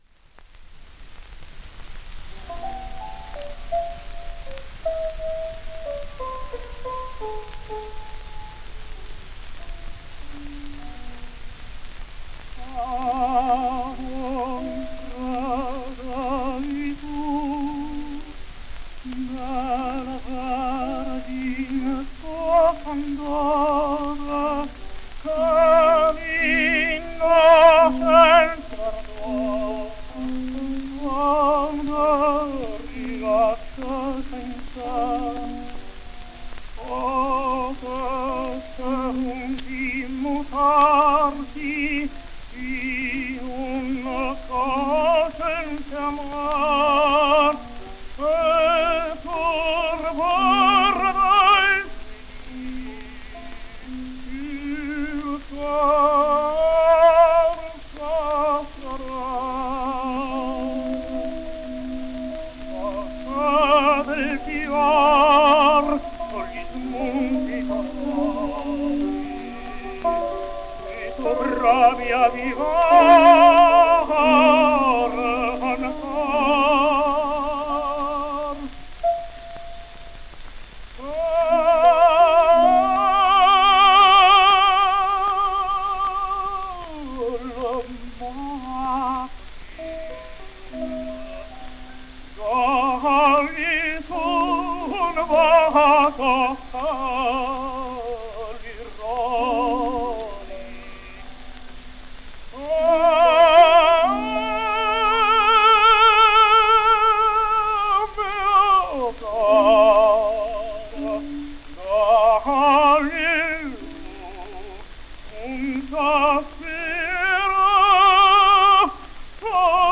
all recorded in Milano